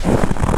STEPS Snow, Walk 03.wav